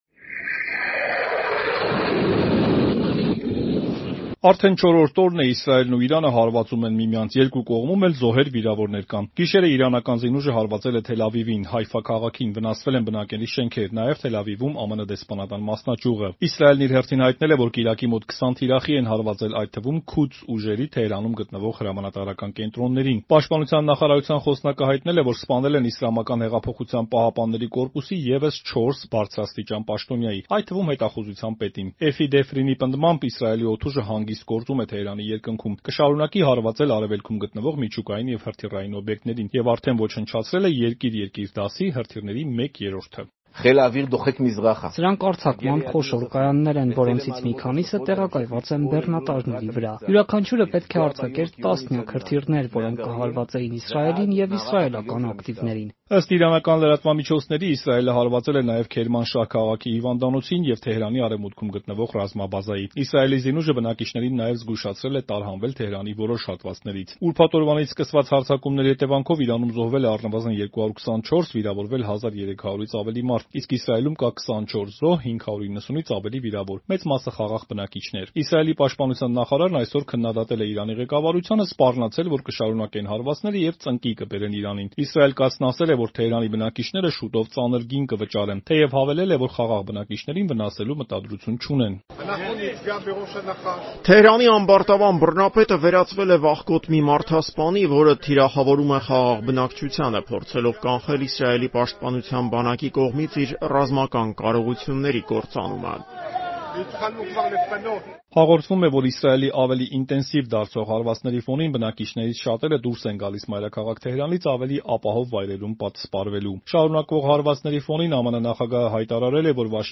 Ռեպորտաժներ